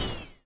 sounds / mob / vex / hurt2.ogg
hurt2.ogg